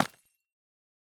Minecraft Version Minecraft Version 21w07a Latest Release | Latest Snapshot 21w07a / assets / minecraft / sounds / block / calcite / break1.ogg Compare With Compare With Latest Release | Latest Snapshot
break1.ogg